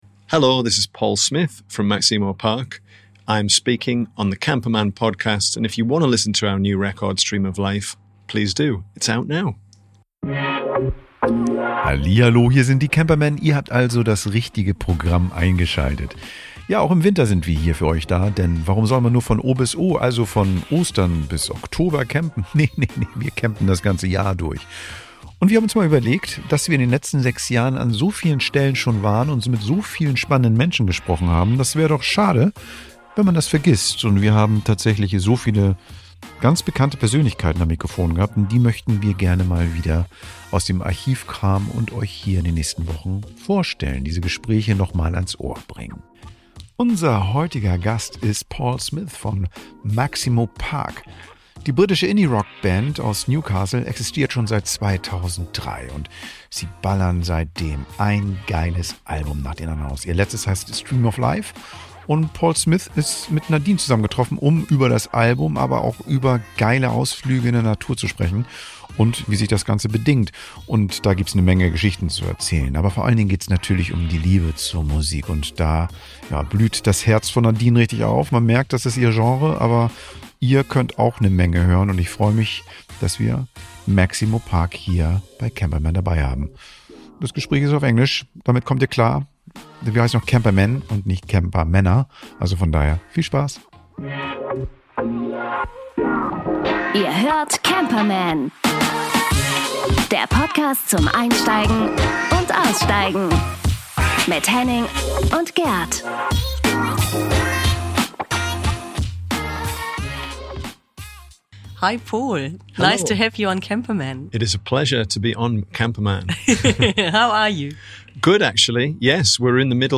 Da passt es, dass sie sich Paul Smith, den Frontman der britischen Band, ans Mikro geholt hat. Sie sprechen über Musik, Inspiration und Campingtrips mit der Familie.